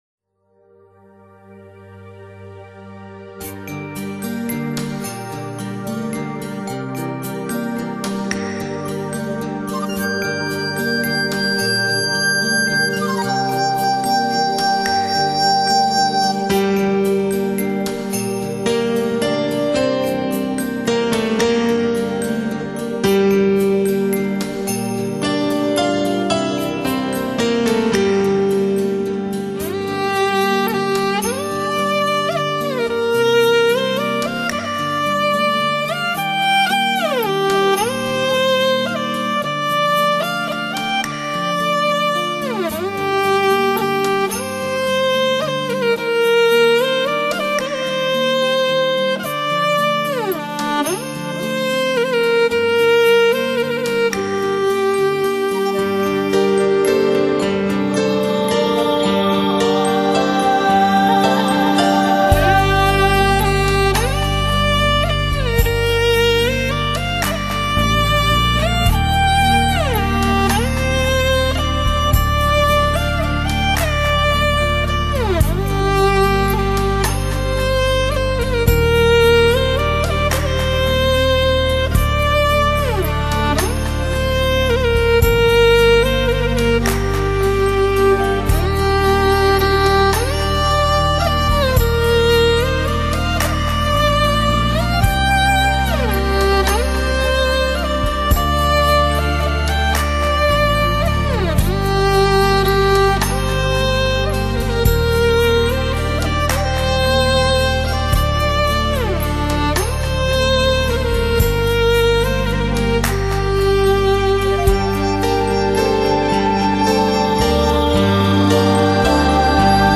马头琴，细腻如小提琴，醇厚如中提琴，深情如大提琴。